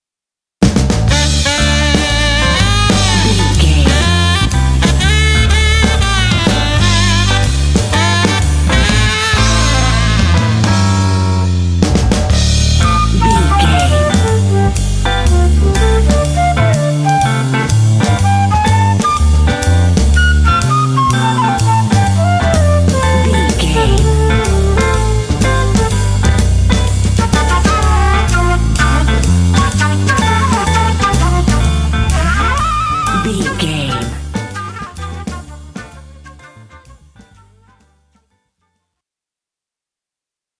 This jazzy sexy track is ideal for story telling games.
Aeolian/Minor
smooth
drums
flute
saxophone
electric guitar
bass guitar
80s
jazz